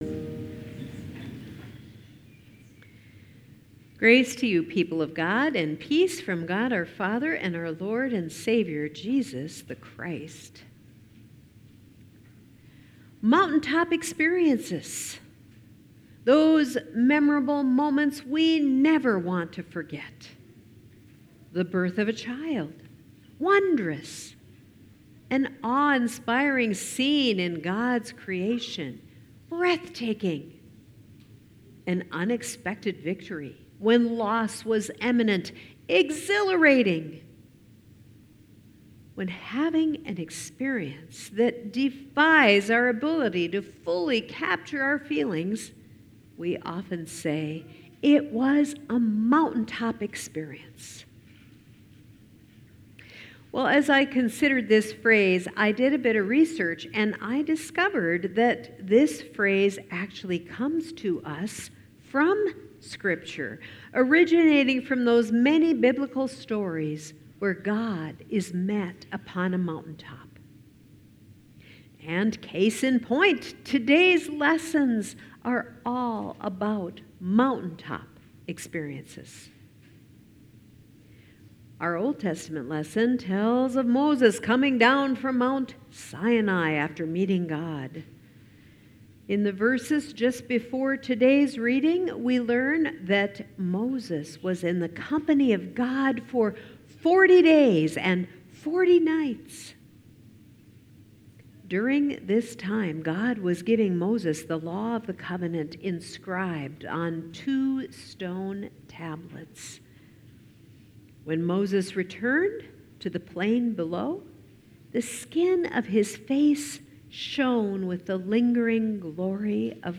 Sermons | Moe Lutheran Church